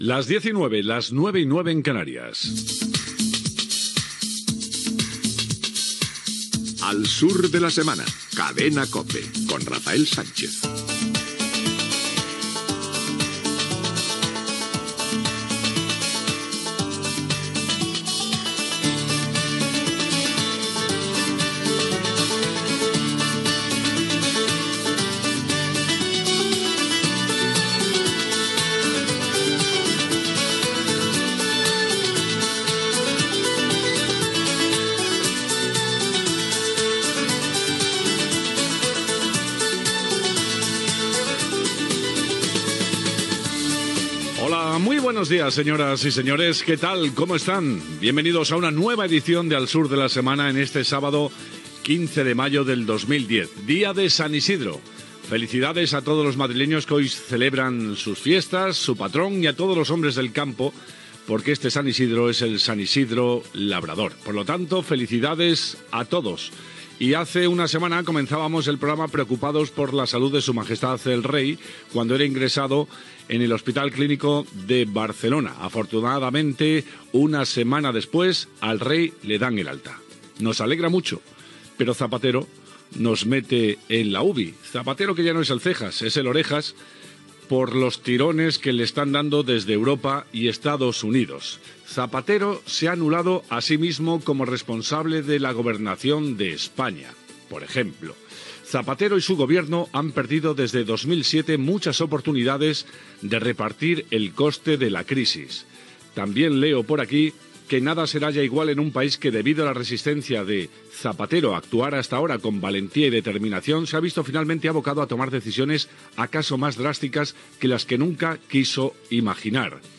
Hora, indicatiu del programa, data, presentació felicitant el dia de Sant Isidre, repàs a l'actualitat política (el rei d'Espanya donat d'alta, la crisi econòmica i el govern de Rodríguez Zapatero, l'Atlético de Madrid), indicatiu.
Info-entreteniment
FM